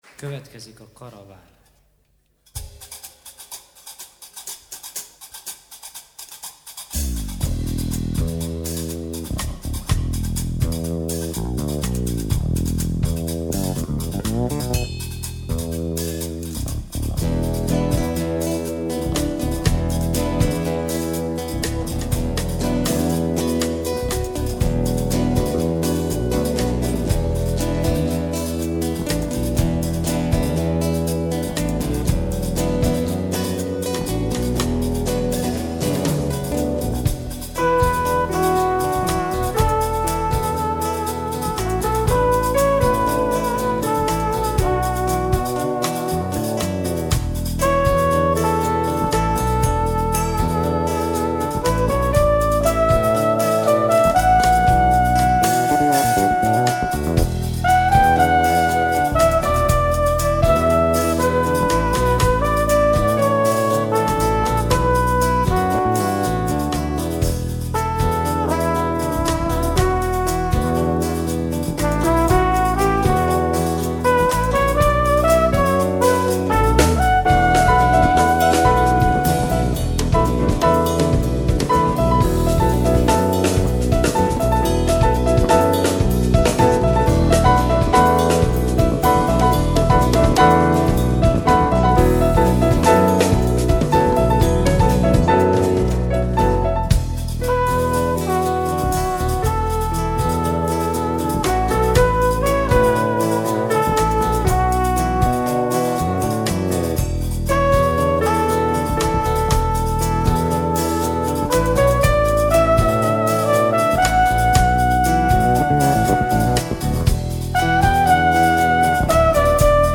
Az utolsó két este unplugged koncert volt.
basszusgitár
gitár
ének, ütőhangszerek
trombita, szárnykürt, hegedű, ének
billentyűs hangszerek
dobok
ének, gembri